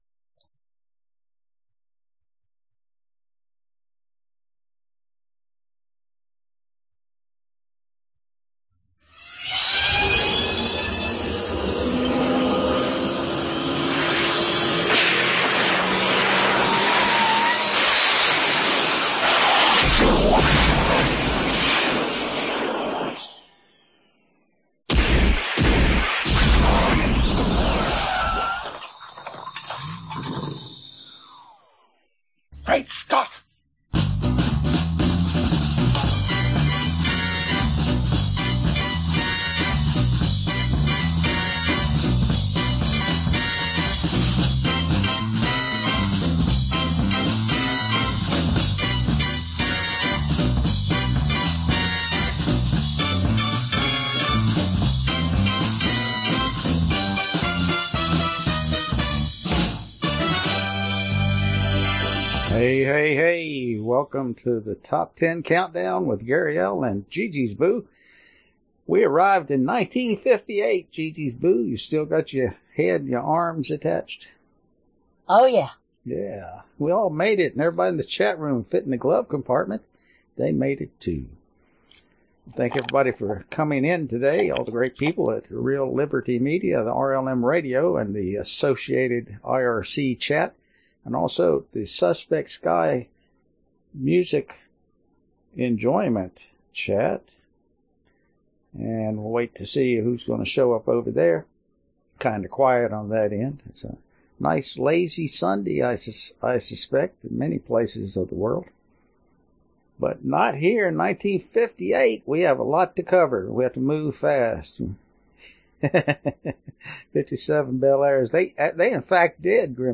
Genre Oldies